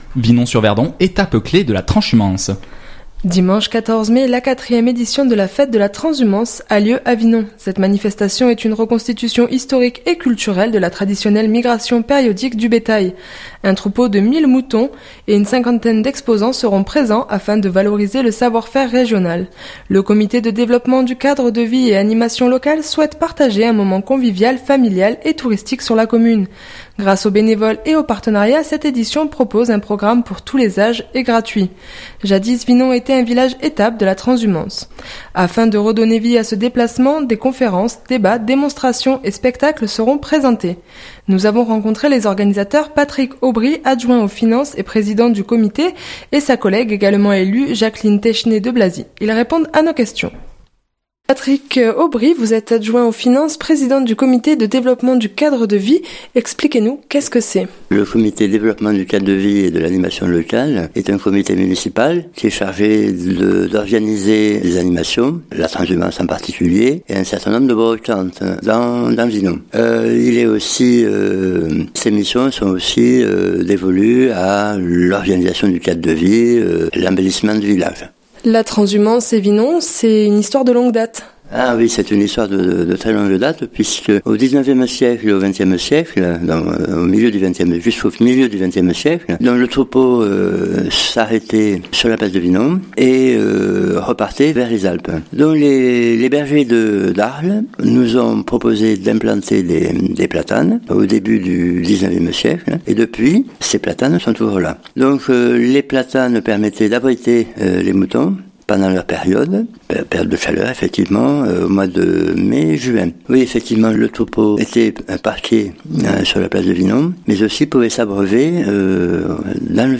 Nous avons rencontré les organisateurs, Patrick Obry, Adjoint aux Finances et Président du comité et sa collègue également élue Jacqueline Teychenne de Blazy.